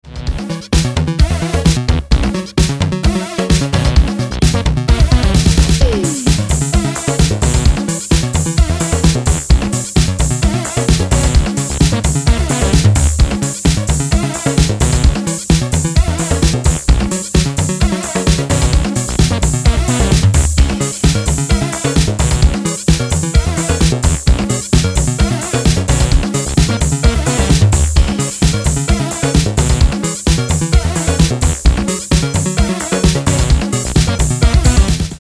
Romantic Computer Techno
The new wave of Italian disco techno.